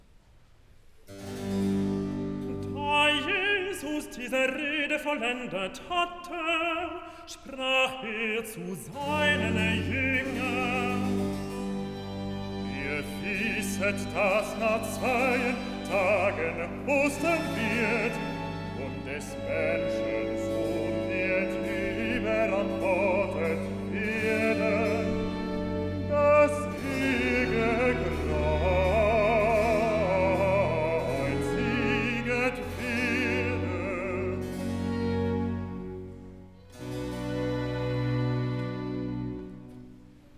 enregistrement haute définition en « live »